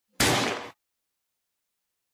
grillehit.ogg